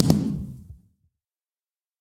largeblast1.ogg